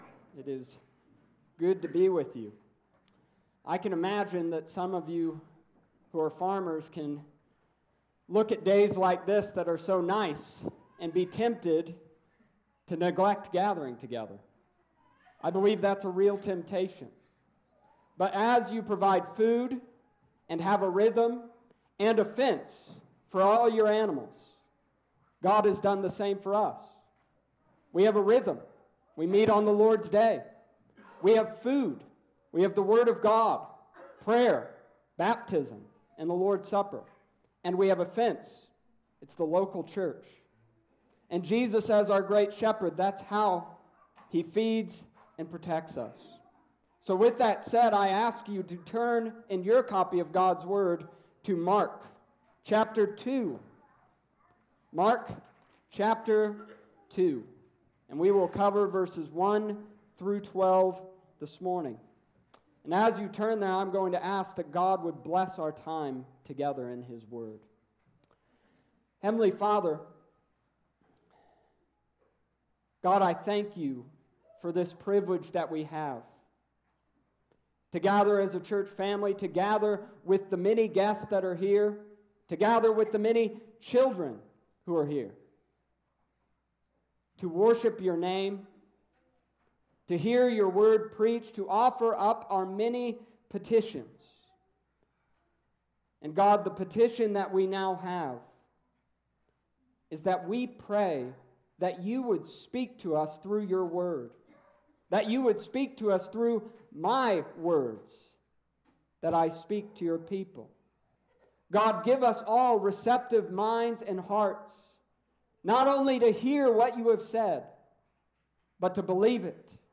Summary of Sermon: This week we discussed Jesus’ return to Capernaum.